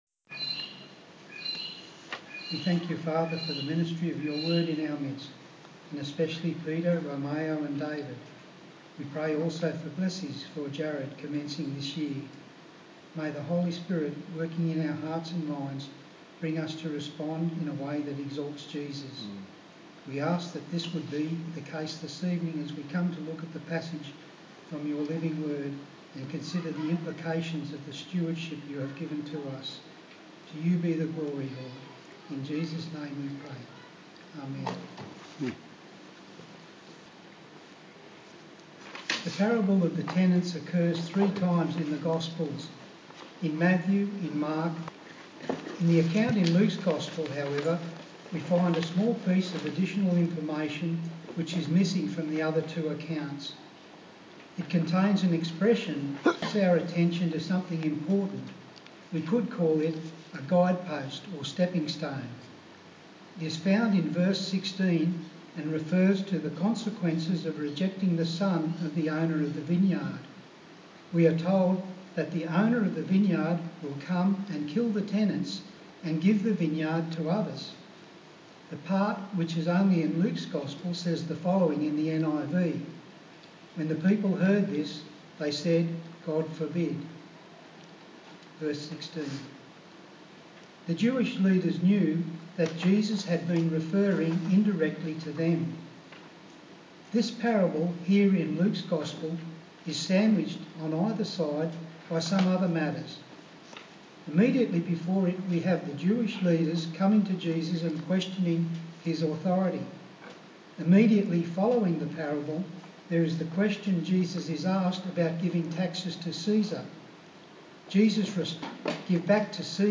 A sermon on the book of Luke